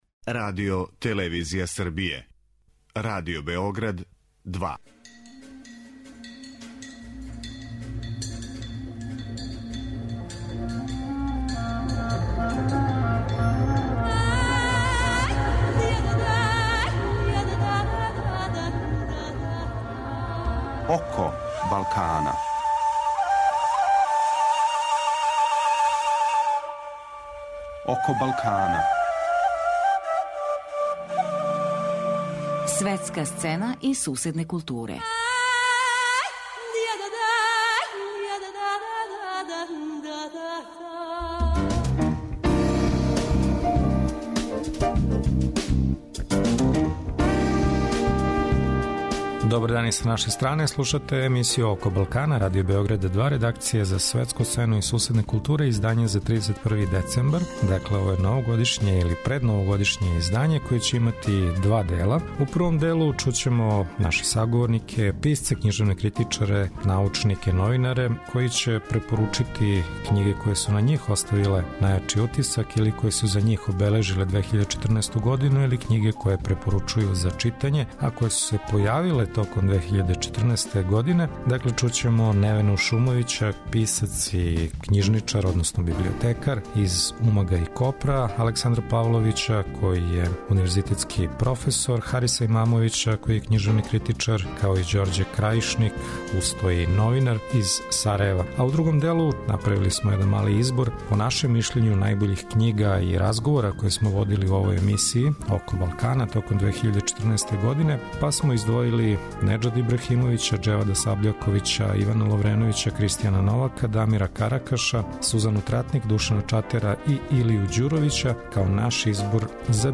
Последња овогодишња емисија Око Балкана резервисана је за преглед године на измаку: које су књиге, аутори и догађаји обележили 2014. годину у региону. Чућемо избор књижевних критичара и писаца и кратак преглед најбољих разговора које смо водили током године.